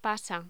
Locución: Pasa
voz